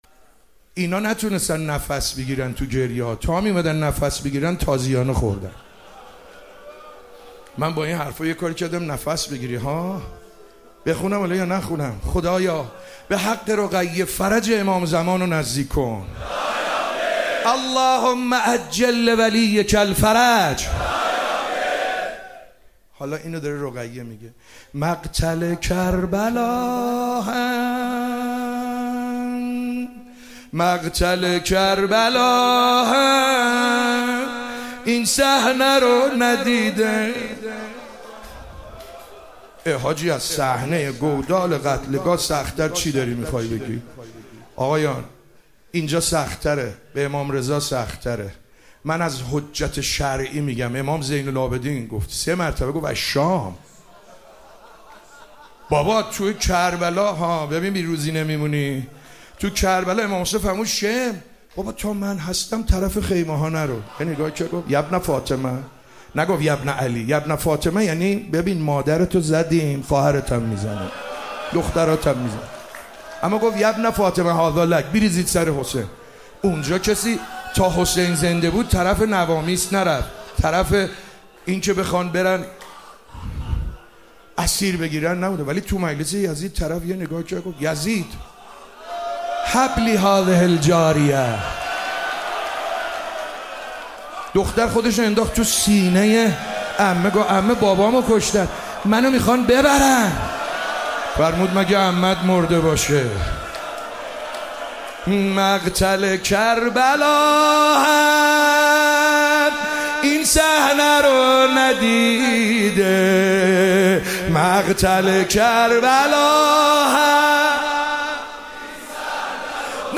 مداحی جدید
شب سوم محرم97 هیئت حسین‌جان مسجد ارک
روضه